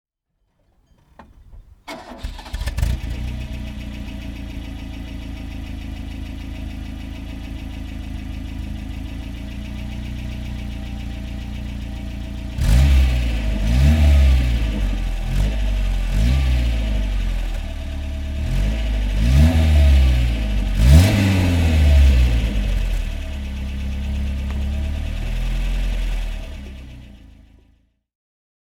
Renault 16 TX (1977) - Starten und Leerlauf